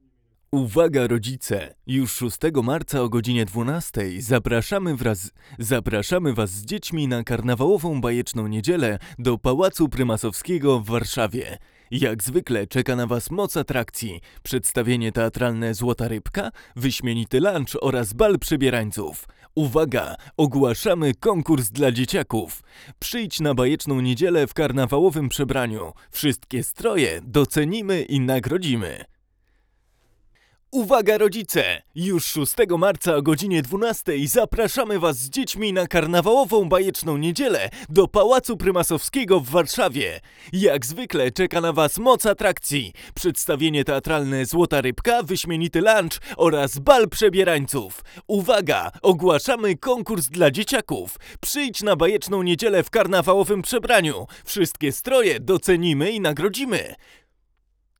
Sontronics Omega + Pre-73
Omega nie wypadła najlepiej w naszych testach i Pre-73 dużo jej nie pomógł, lekko tylko maskując skromne i takie dość hmmmm, puszkowate brzmienie tego modelu.